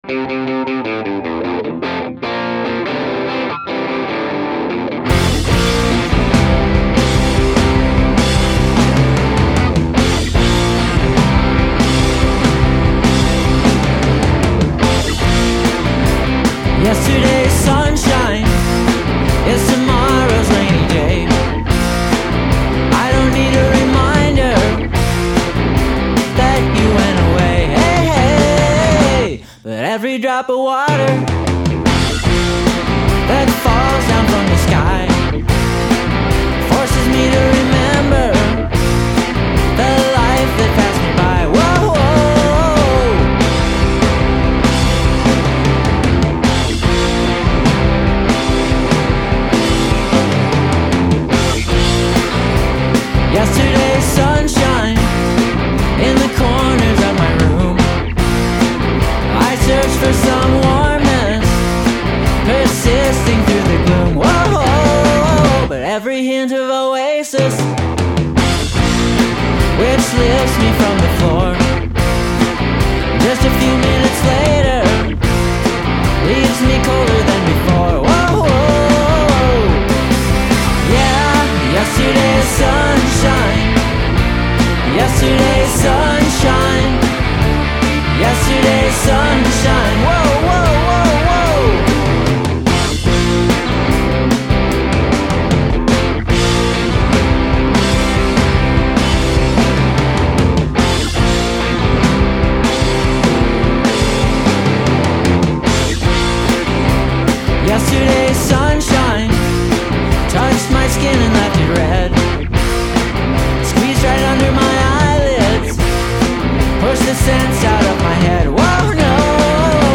vocals, rhythm guitar
lead guitar
bass
drums